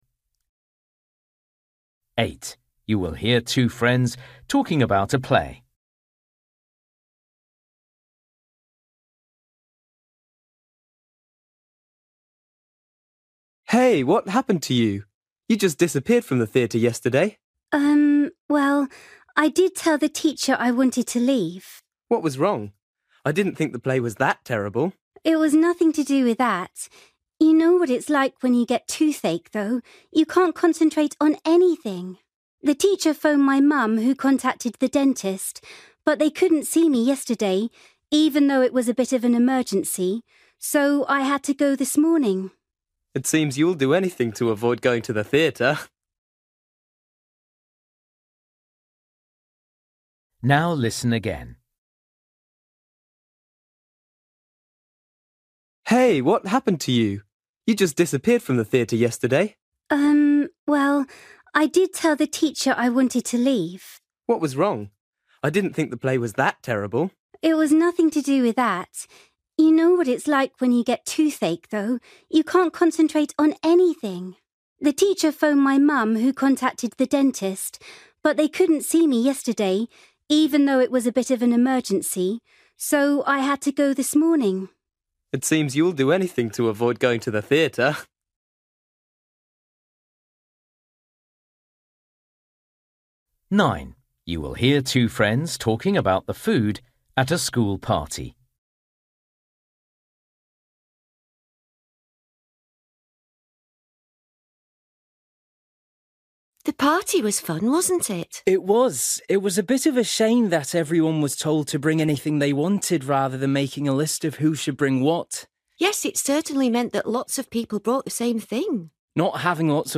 Listening: everyday short conversations
8   You will hear two friends talking about a play. Why did the girl leave the theatre early?
10   You will hear two friends talking about a soccer match they both watched on TV. Why was the boy disappointed?
12   You will hear a girl talking about a blog she has started writing. How does she feel about it?